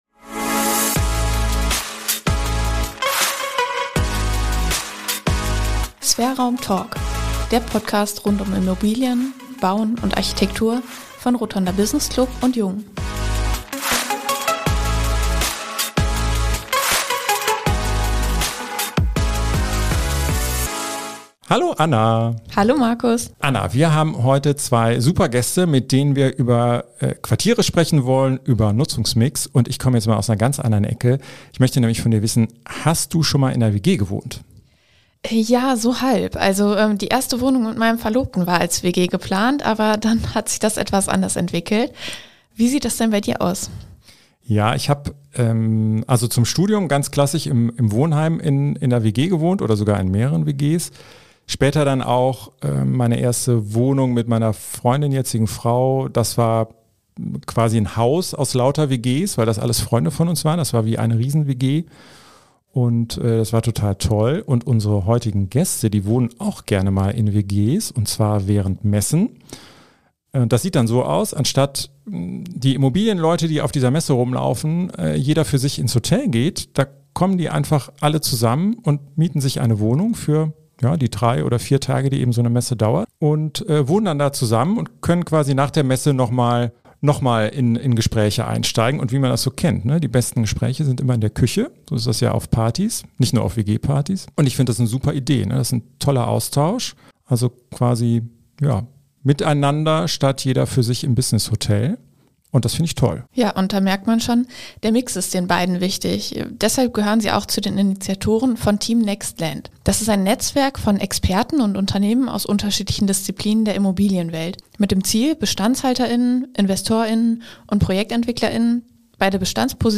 Ein lebhaftes Gespräch über den Trend zu Mischnutzungen, Konflikte unterschiedlicher Nutzergruppen, die Notwendigkeit von Betreiber-Stukturen und ausufernde WG-Parties.